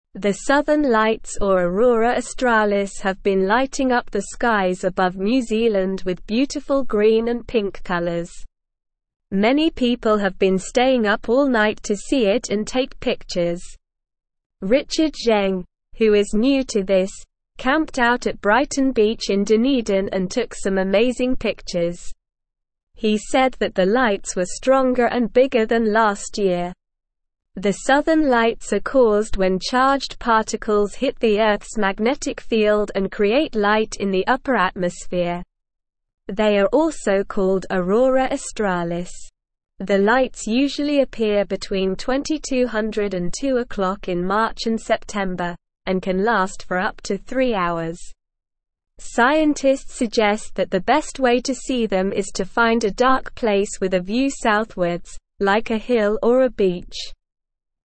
Slow
English-Newsroom-Beginner-SLOW-Reading-Pretty-Colors-in-the-Sky-at-Night.mp3